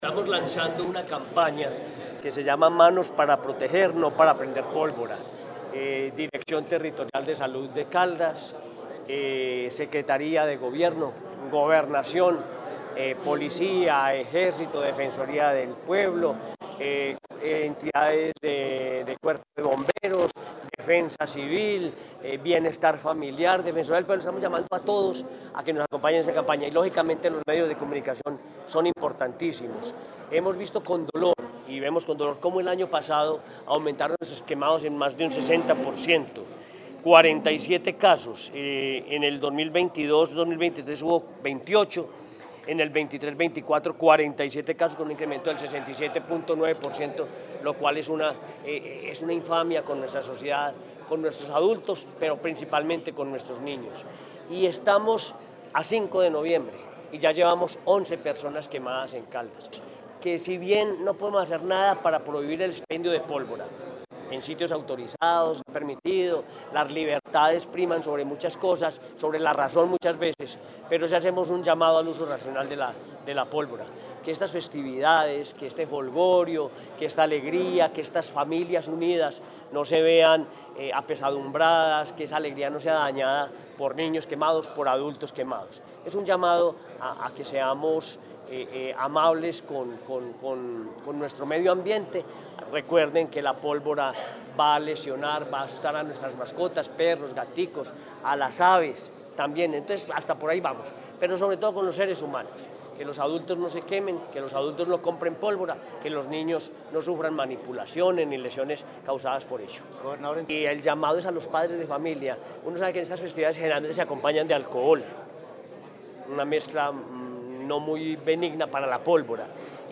Henry Gutiérrez Ángel, Gobernador de Caldas.
AUDI-HENRY-GUTIERREZ-ANGEL-GOBERNADOR-DE-CALDAS-TEMA-LANZAMIENTO-CAMPANA-POLVORA.mp3